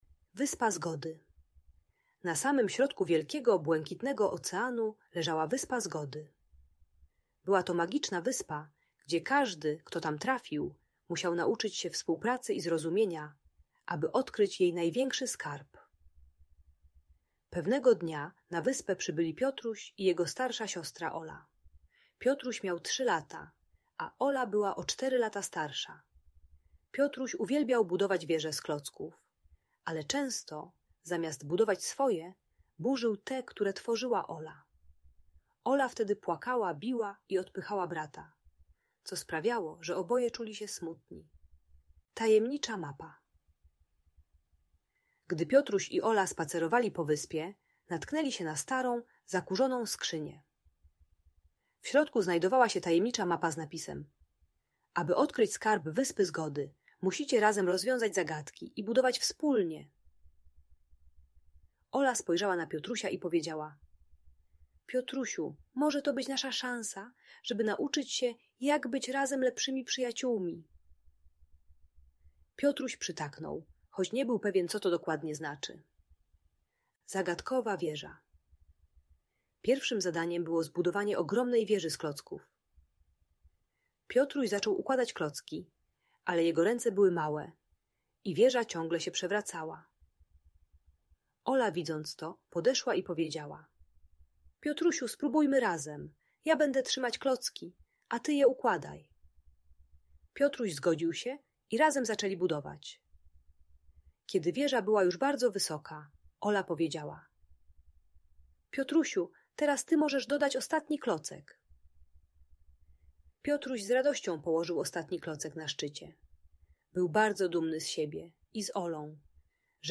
Magiczna story o Wyspie Zgody - Audiobajka